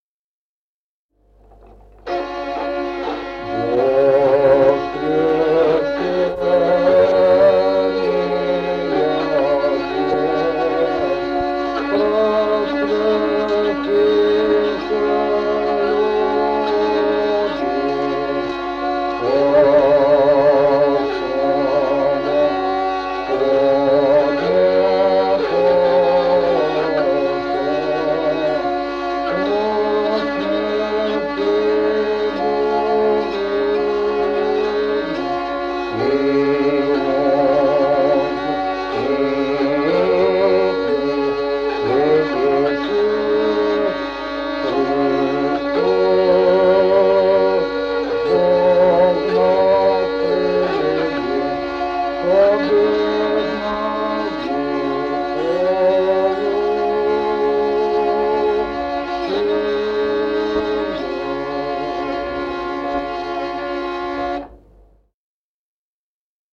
Музыкальный фольклор села Мишковка «Воскресения день», ирмос первой песни канона Пасхи, глас 1-й. И 0102-04.